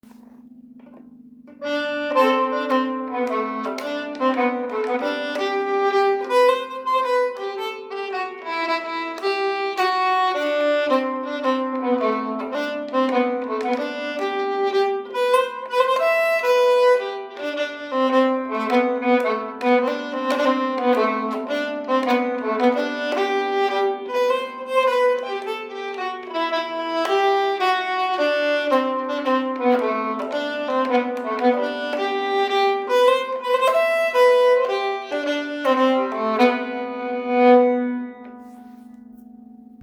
ex. andrastämma) Stämmlåten andrastämma.m4a
Schottis